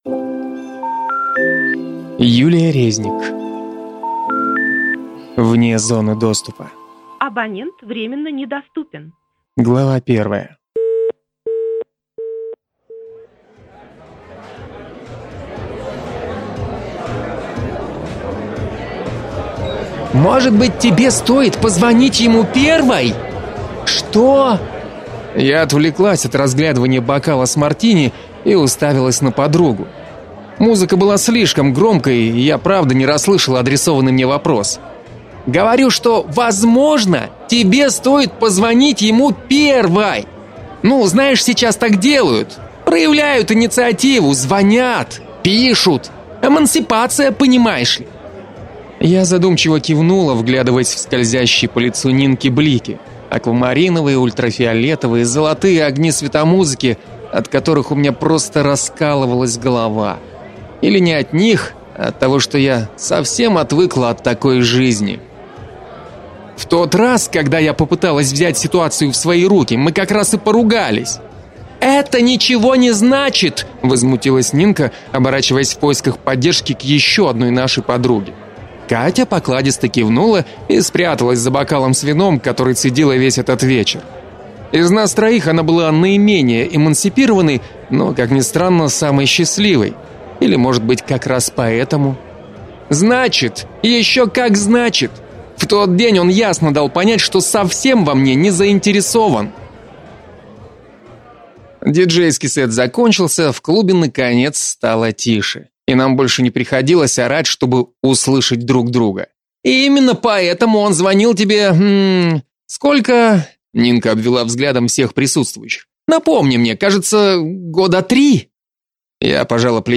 Аудиокнига Вне зоны доступа | Библиотека аудиокниг